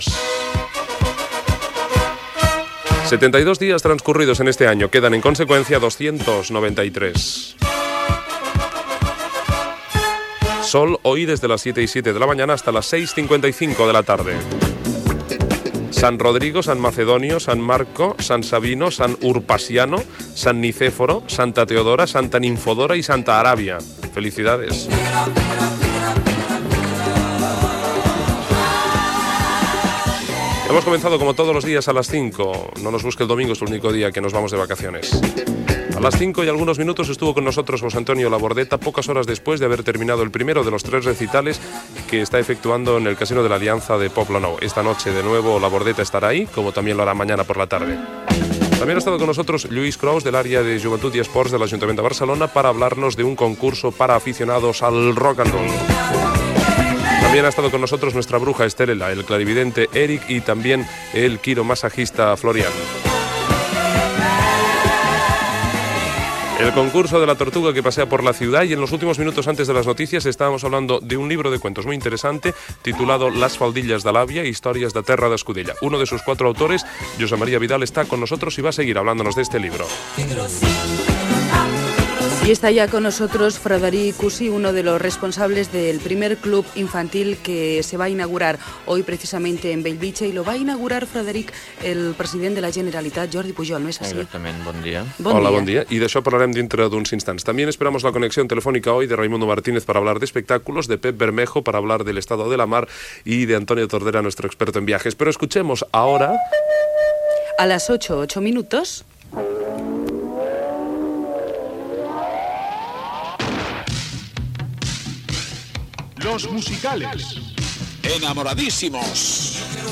Entreteniment
Presentador/a